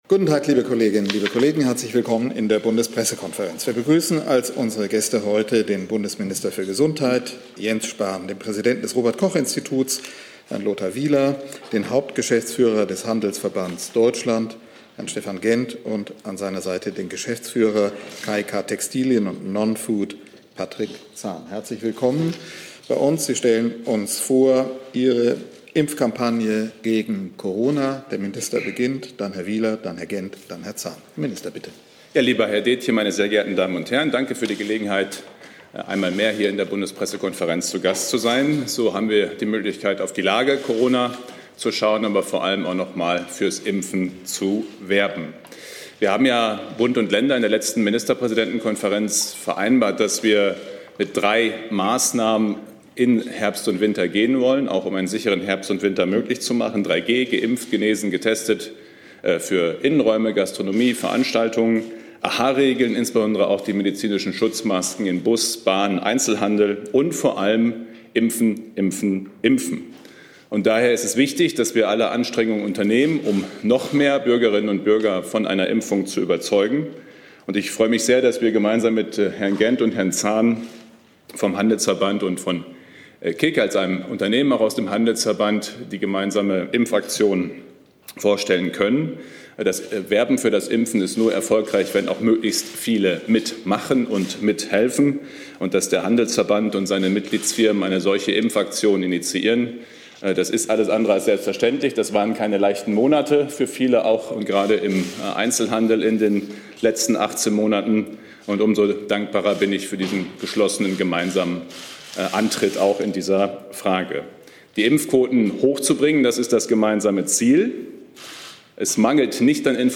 BPK - Spahn, Wieler & Co zur Impfkampagne gegen Corona - 8. September 2021 ~ Neues aus der Bundespressekonferenz Podcast